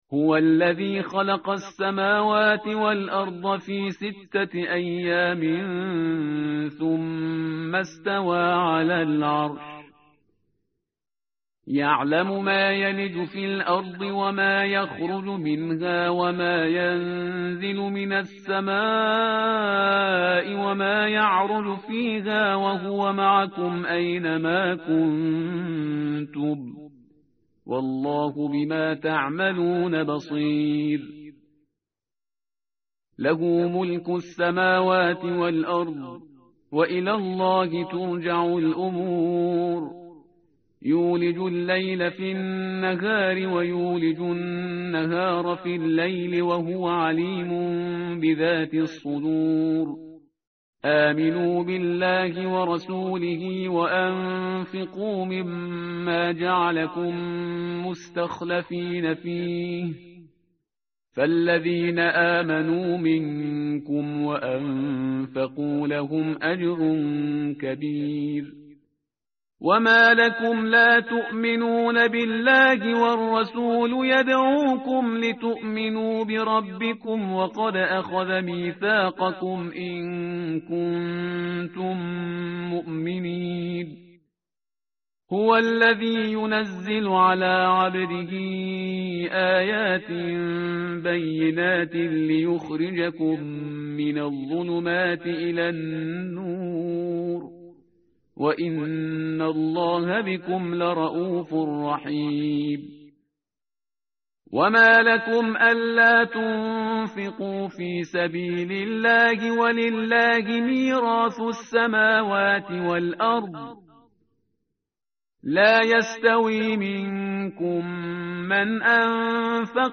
tartil_parhizgar_page_538.mp3